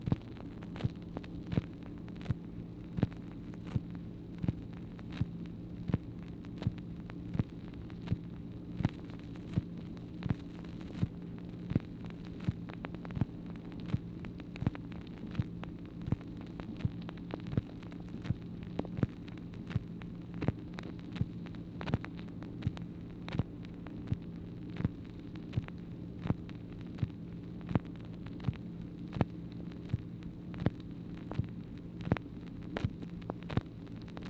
MACHINE NOISE, March 25, 1964
Secret White House Tapes | Lyndon B. Johnson Presidency